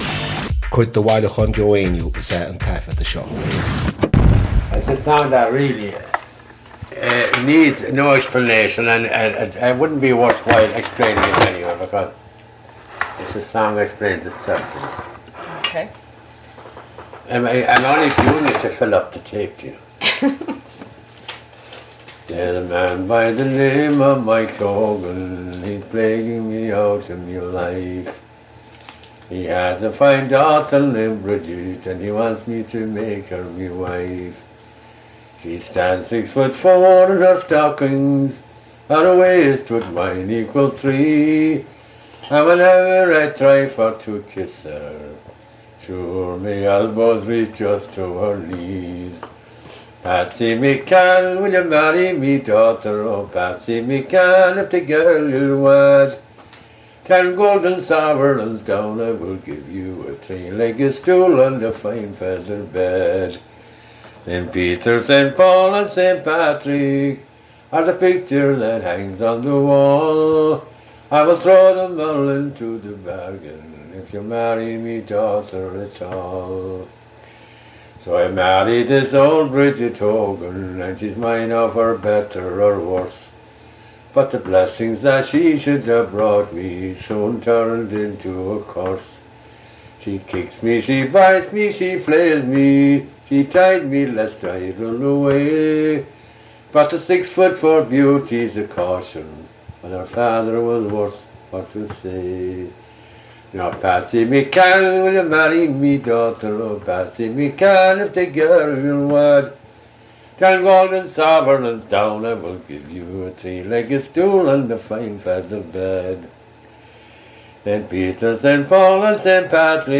• Catagóir (Category): song.
• Ainm an té a thug (Name of Informant): Joe Heaney.
• Suíomh an taifeadta (Recording Location): Bay Ridge, Brooklyn, New York, United States of America.
• Ocáid an taifeadta (Recording Occasion): private.